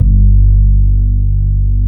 FRETLESSG2-L.wav